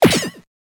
misshit.wav